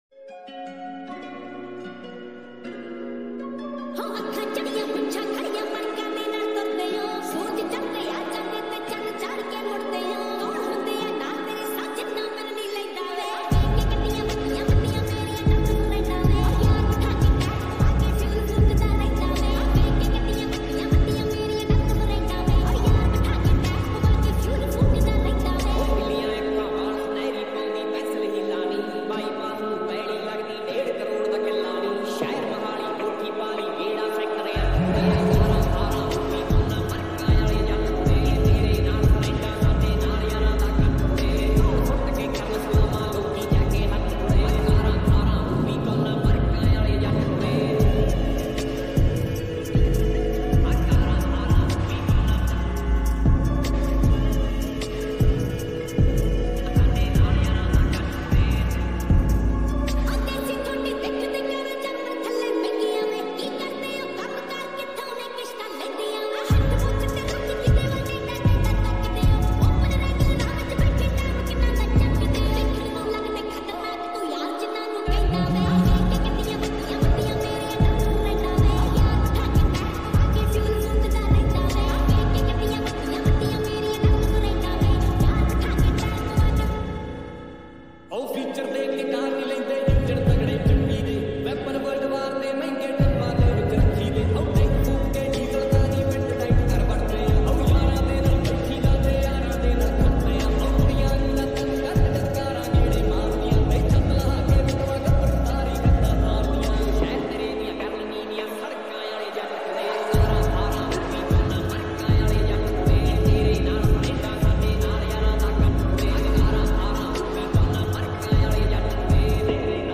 Full song based slowed reverb